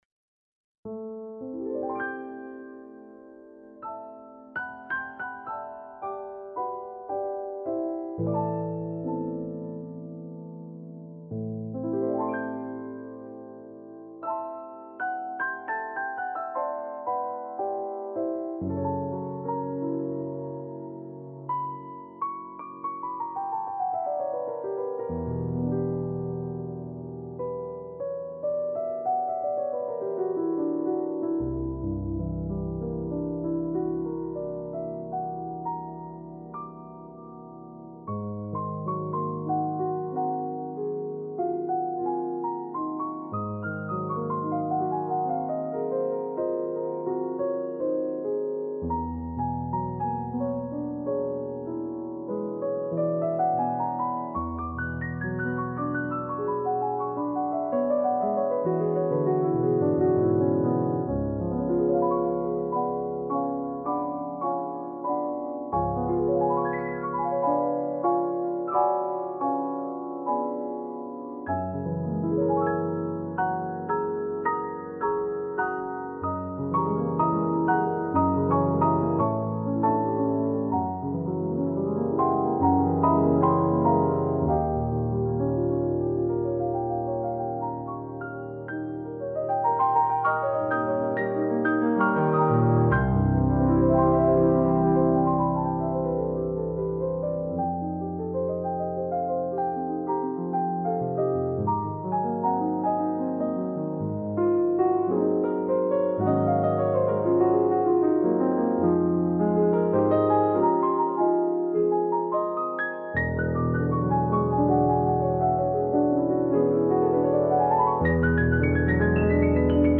Musica per pianoforte